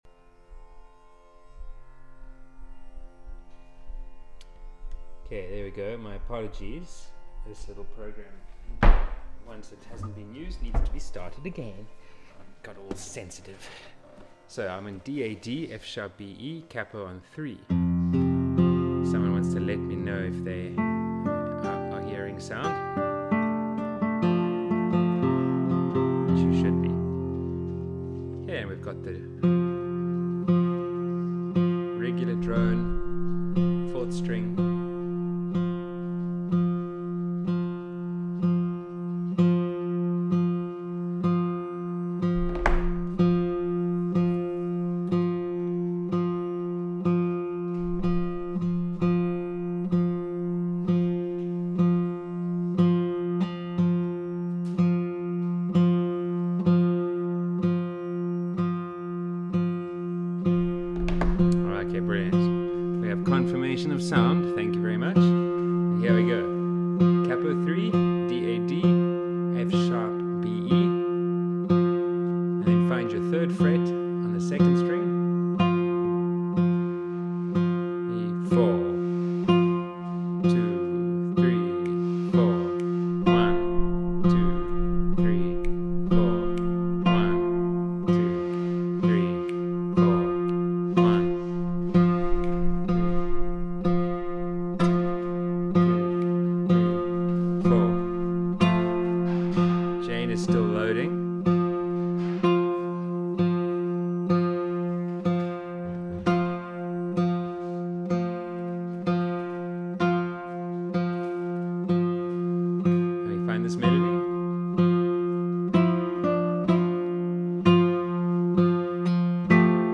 Video lessons uploaded after every online group class.